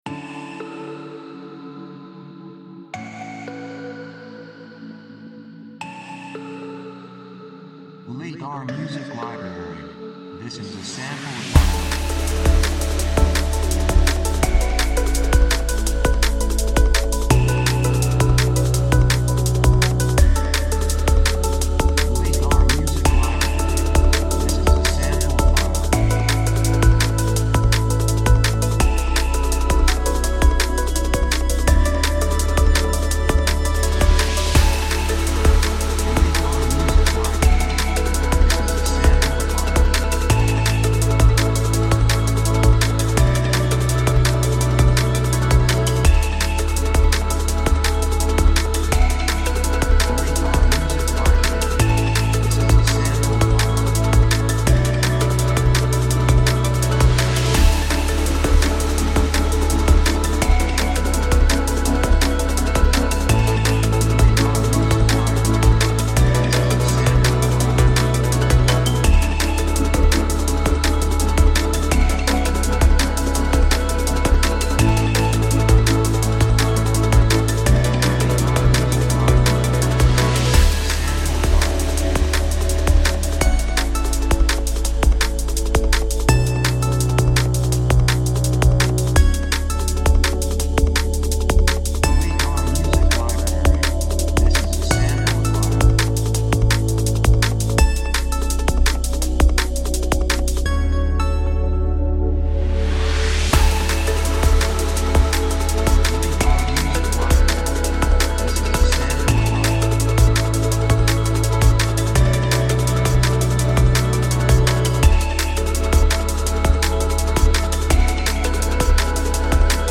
2:59 167 プロモ, エレクトロニック
雰囲気エネルギッシュ, 幸せ, せわしない, 高揚感, 決意, 夢のような, 喜び
曲調ポジティブ
楽器シンセサイザー
サブジャンルドラムンベース
テンポとても速い